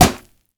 punch_head_weapon_bat_impact_02.wav